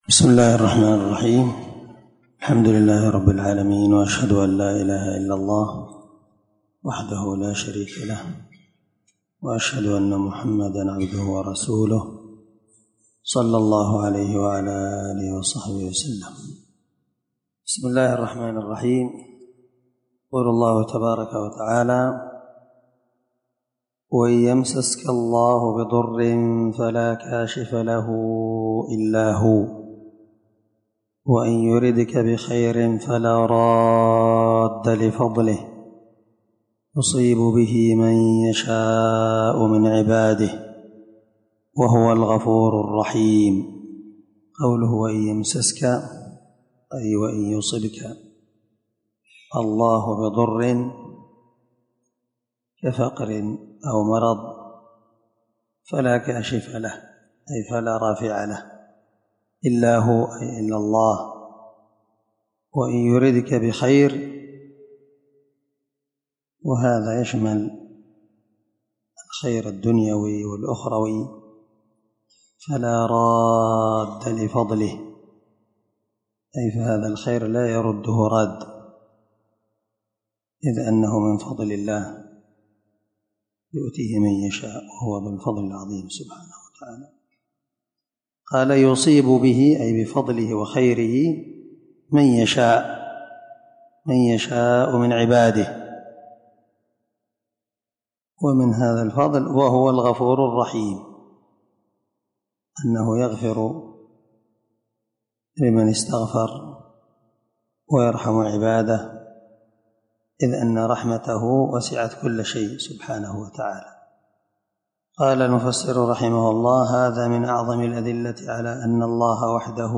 619الدرس 35 تفسير آية ( 107- 109) من سورة يونس من تفسير القران الكريم مع قراءة لتفسير السعدي
دار الحديث- المَحاوِلة- الصبيحة.